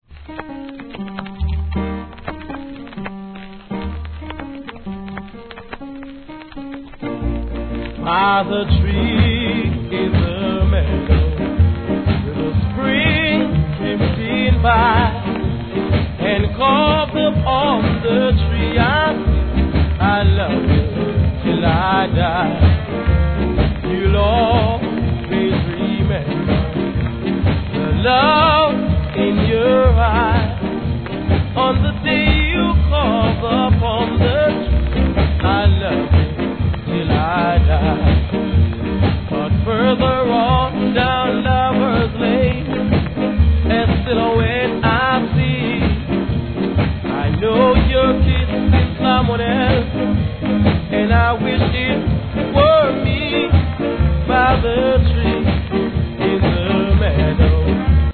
B 〜 C (若干の歪みありますがPLAY問題なし)
REGGAE